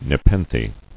[ne·pen·the]